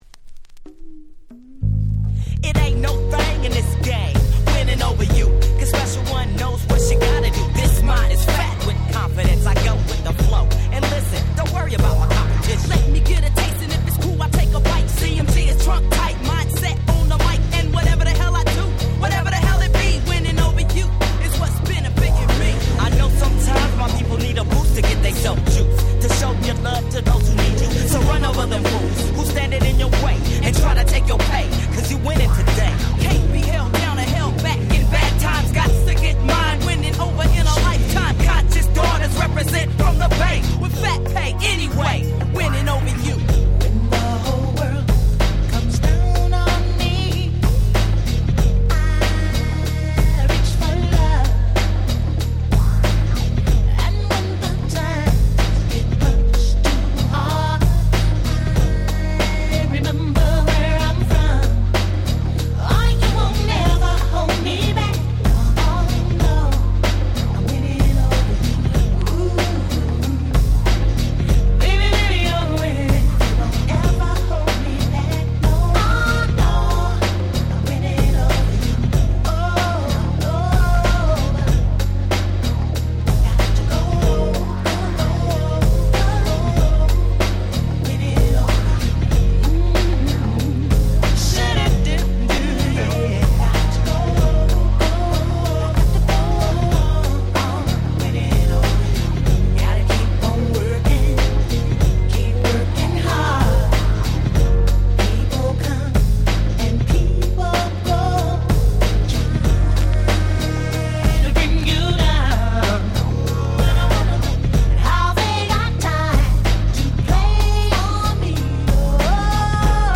94' Nice R&B/Hip Hop Soul !!